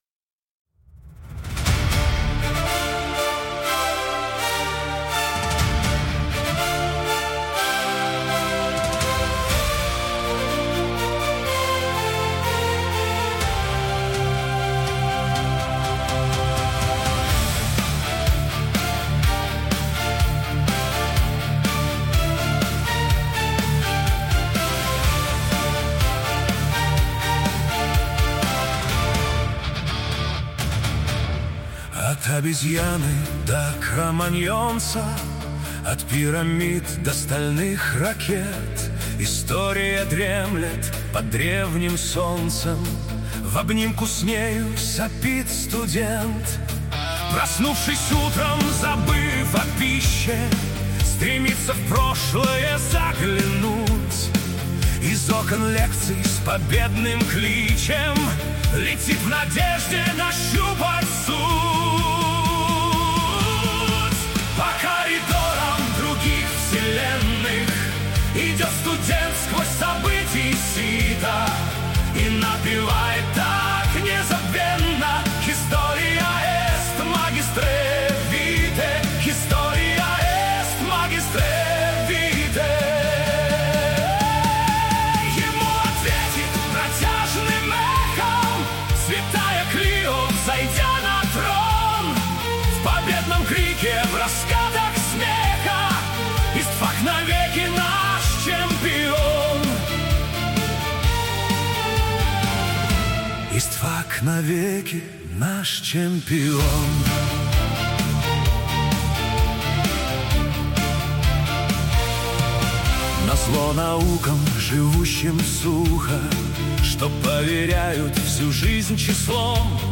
Истфак - чемпион эпик-рок в обработке ИИ
Истфак_-_чемпион_эпик-рок_2.mp3